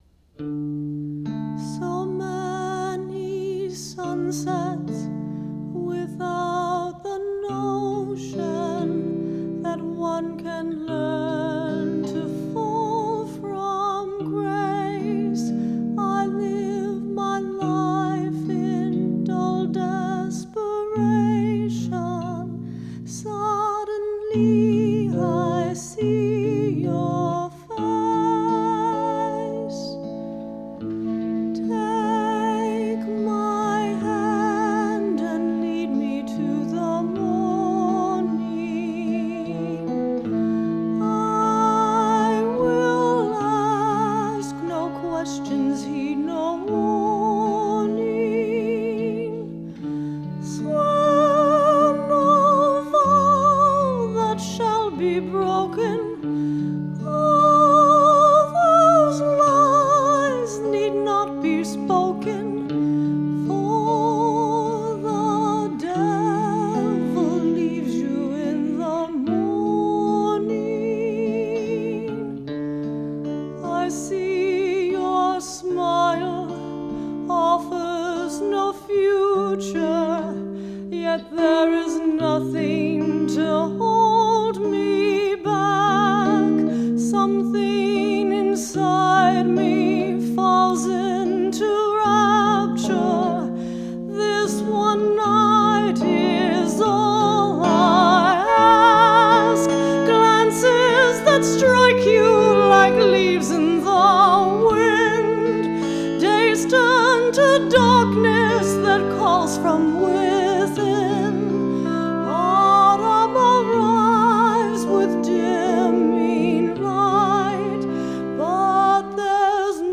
My mother sang the first “Art Song” I’d ever composed for her as a teenager, a rather odd Ophelia-mad-scene-esque number called “Tree Top Singing”, and the somewhat more appropriate romantic ballad “Lead Me to the Morning” from my musical “Witches” – although arguably the final lyric “make me burn, then leave me in the morning” isn’t the most appropriate for a wedding either?